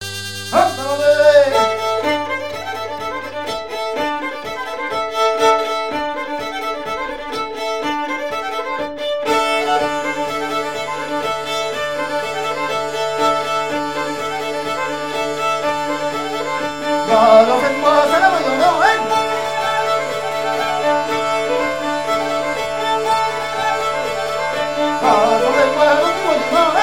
Bocage vendéen
danse : branle : avant-deux ;
Pièce musicale éditée